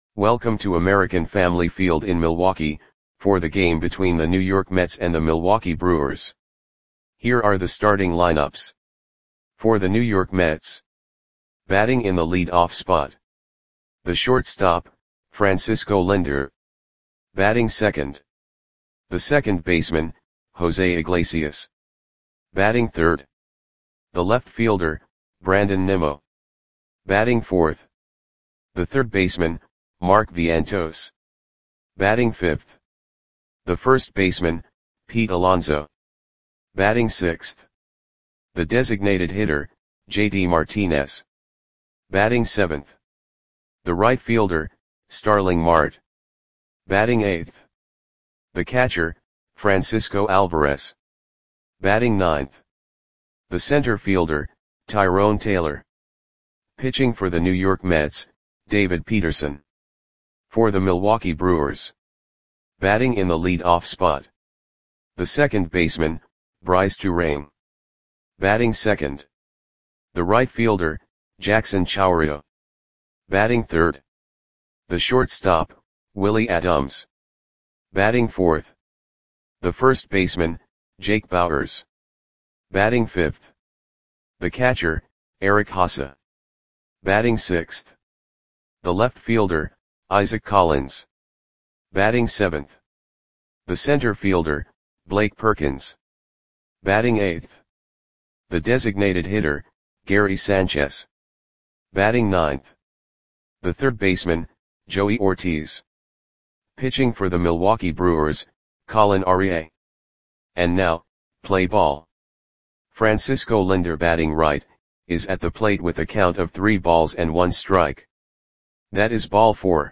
Audio Play-by-Play for Milwaukee Brewers on September 29, 2024
Click the button below to listen to the audio play-by-play.